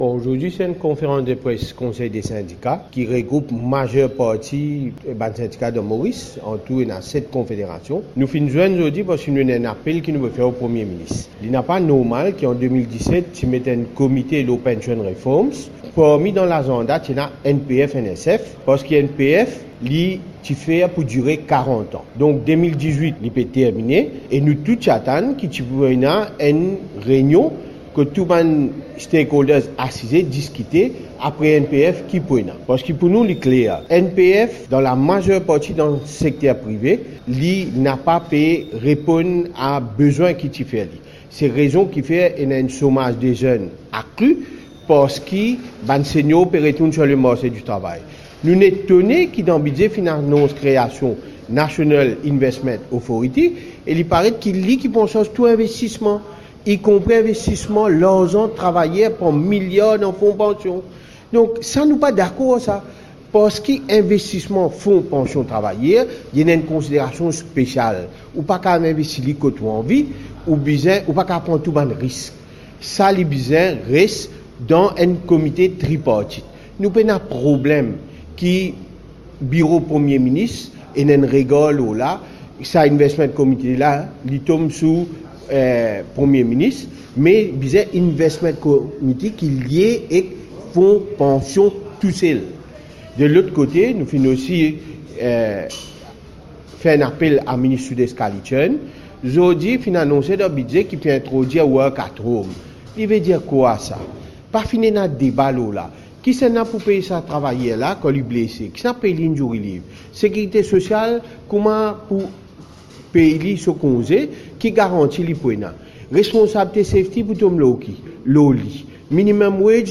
Le conseil des syndicats rencontrait la presse, ce mardi 26 juin, à Port-Louis.